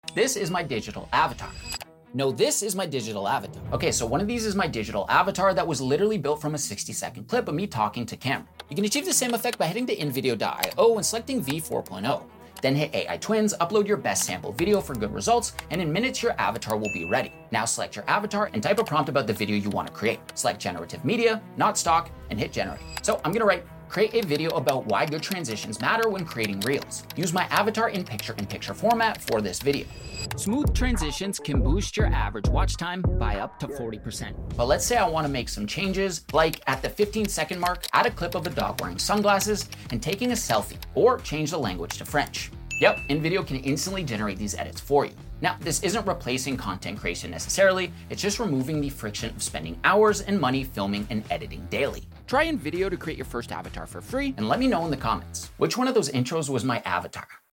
Which one’s real — and which is my AI twin?